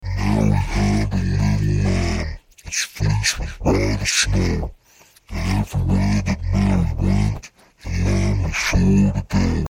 Download Voice Changer sound effect for free.
Voice Changer